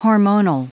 Prononciation du mot : hormonal